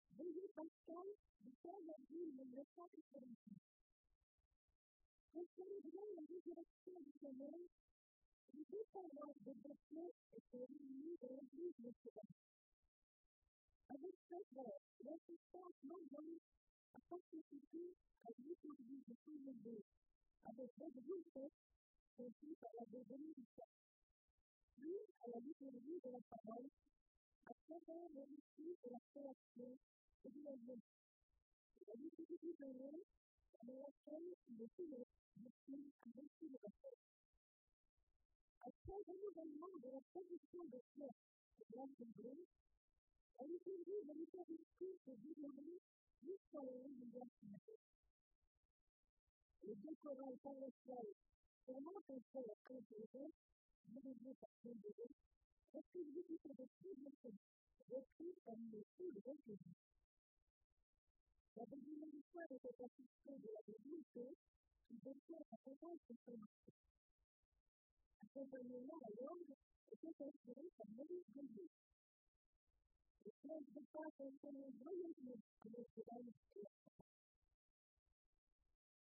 Veillée pascale à Notre-Dame de Beaupréau
cérémonie religieuse